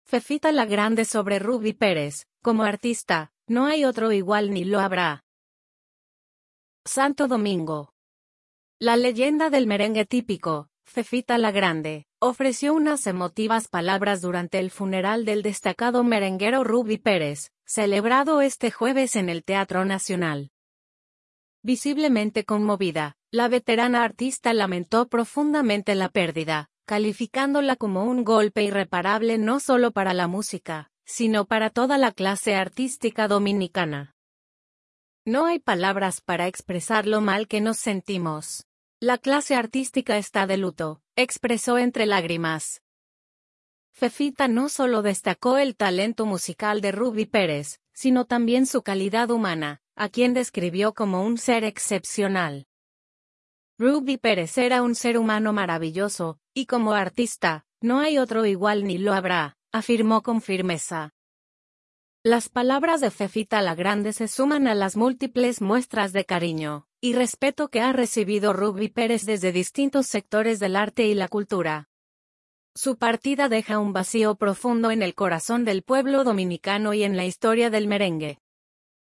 Santo Domingo.– La leyenda del merengue típico, Fefita La Grande, ofreció unas emotivas palabras durante el funeral del destacado merenguero Rubby Pérez,